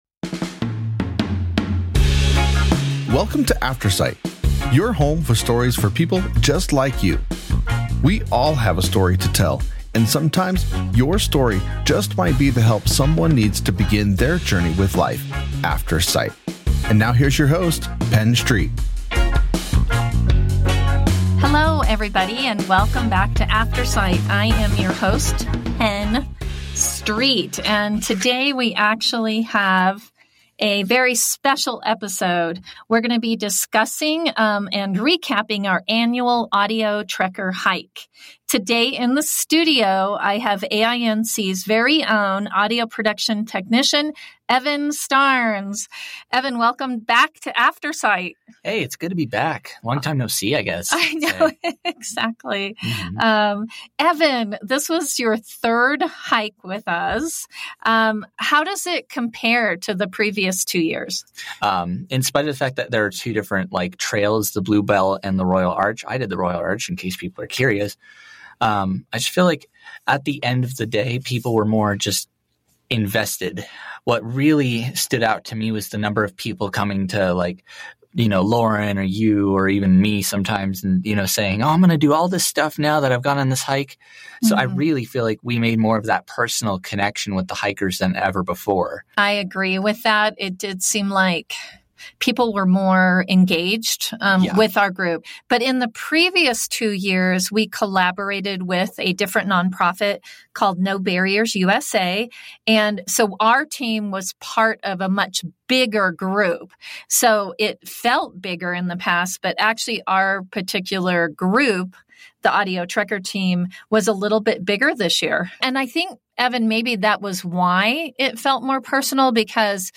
This year, Aftersight earned top honors in the On-Location category for the “Audio Trekkers Hike” episode from The Blind Chick podcast . This episode captured our annual event, where blind and low-vision individuals hike trails in the Rocky Mountains, such as the Chautauqua Royal Arch and Bluebell trails. On-location interviews highlighted hikers’ experiences, excitements, fears, and the profound impact on their lives.